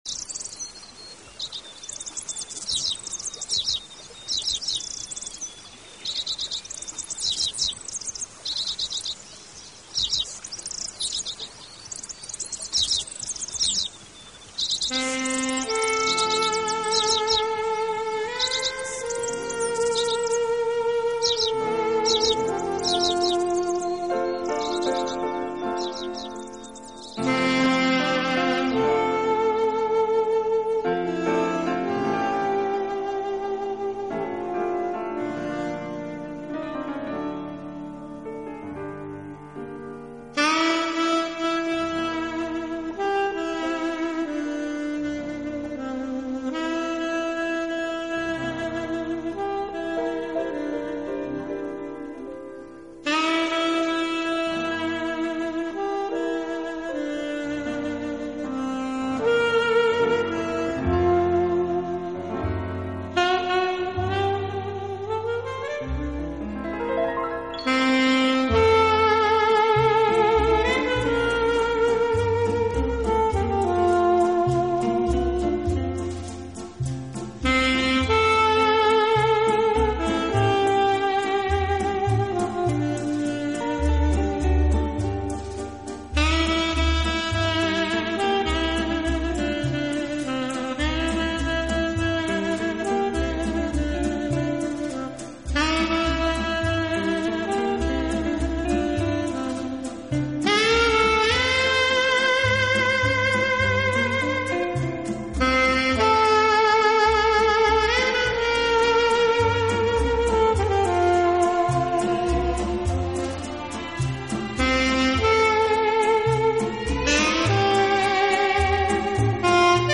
耳熟能详的经典音乐小品，点缀着鸟儿的鸣叫